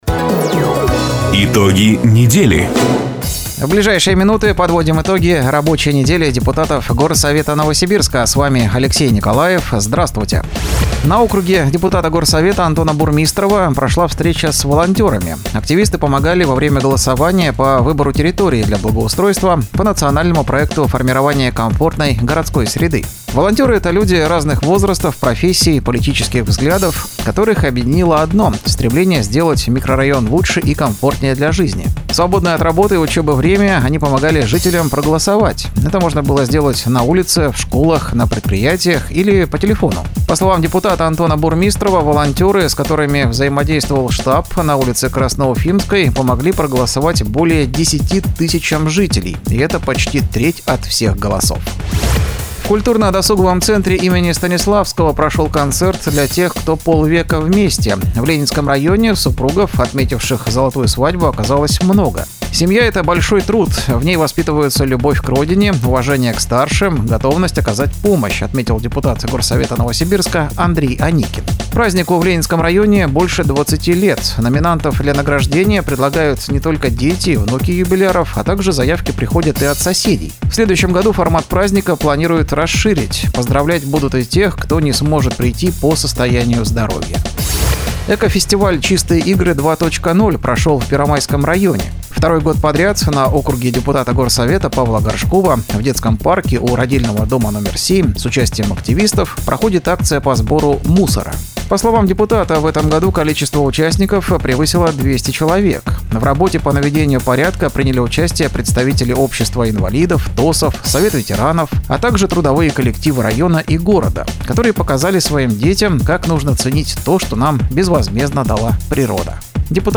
Запись программы "Итоги недели", транслированной радио "Дача" 01 июня 2024 года.